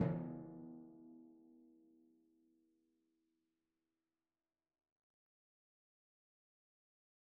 Timpani4_Hit_v3_rr2_Sum.wav